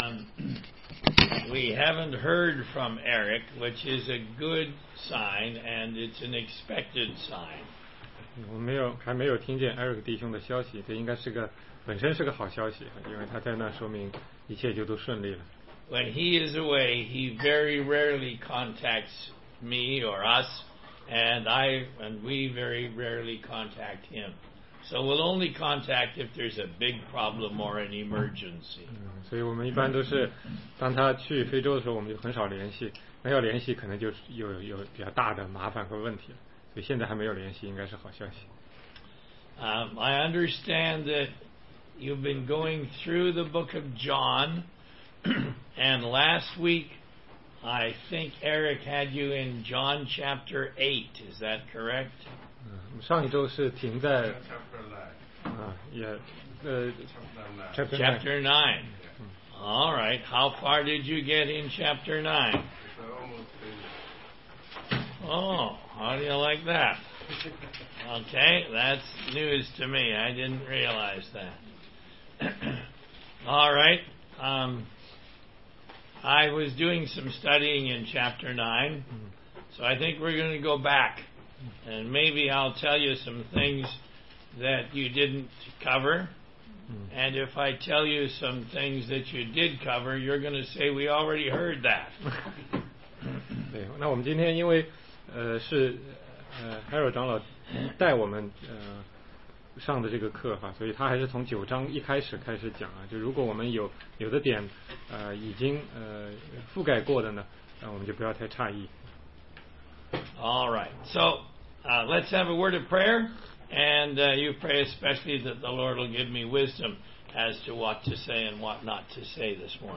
16街讲道录音 - 约翰福音第9章续